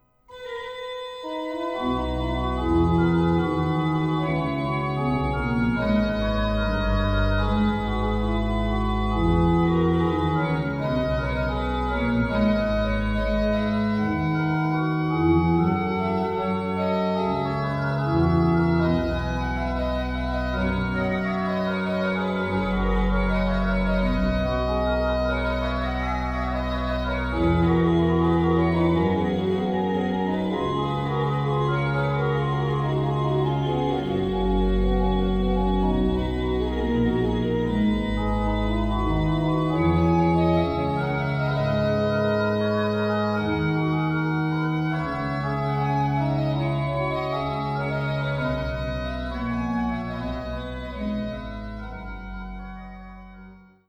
orgue flamand (Cattiaux) de l’église de Beurnevésin